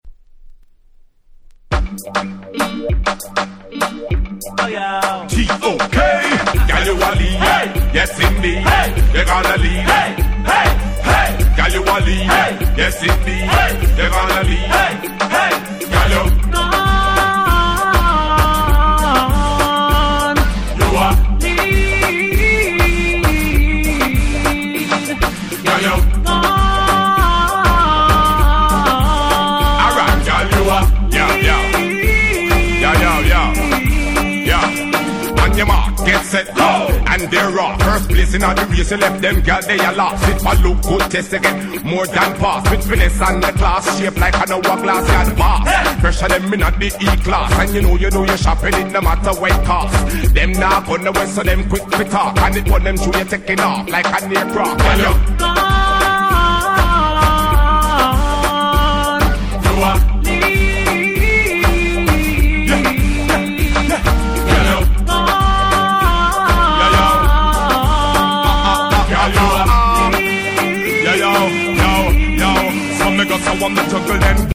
03' Big Hit Dancehall Reggae !!